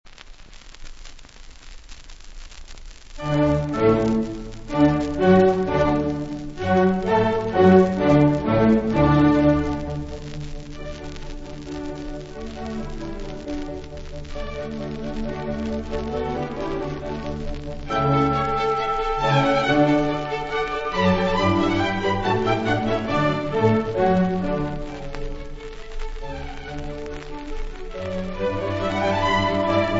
Divertimento No. 2 in Re maggiore : K 131 ; Minuetto e trio : Divertimento No. 15 in Si bem. magg. / W. A. Mozart ; Orchestra Filarmonica Reale ; Thomas Beecham, direttore